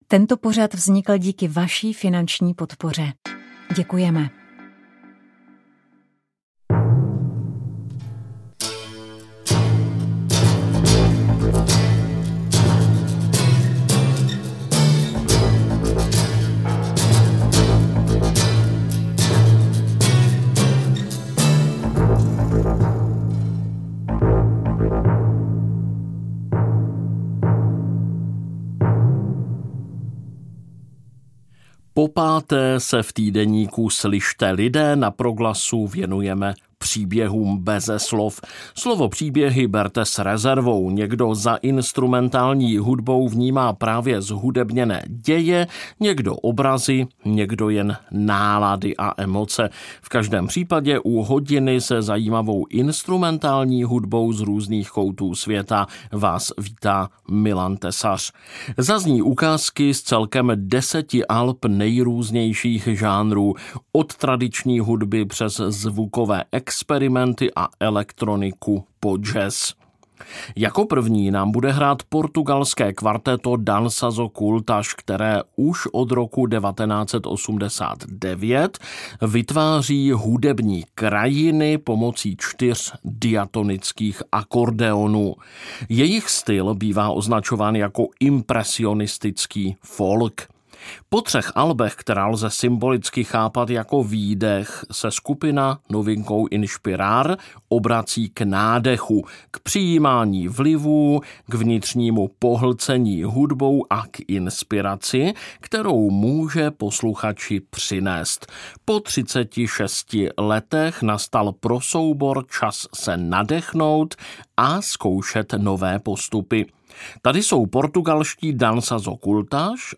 Hudba tria a jeho hostů je akustická a taneční.